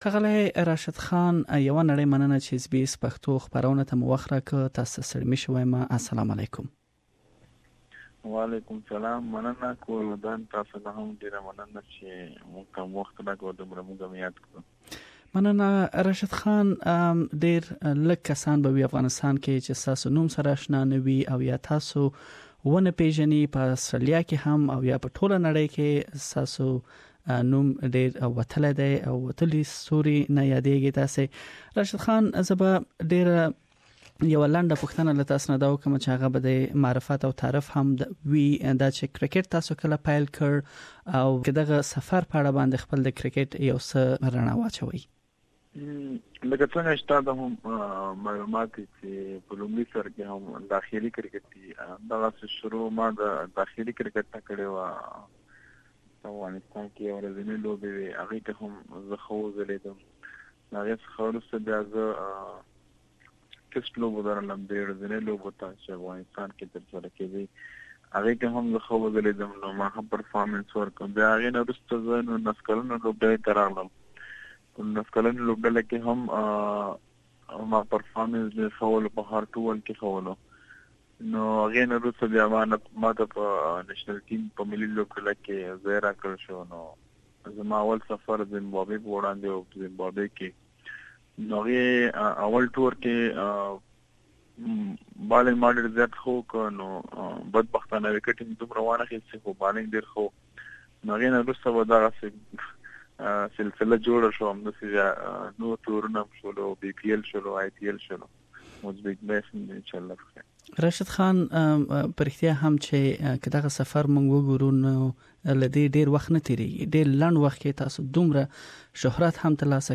Please listen to the SBS exclusive interview with Rashid Khan here.